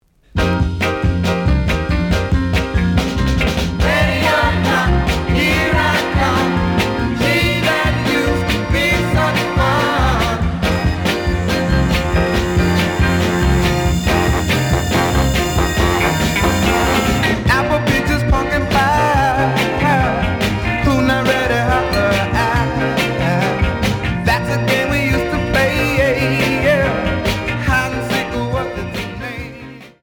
試聴は実際のレコードから録音しています。
●Genre: Soul, 60's Soul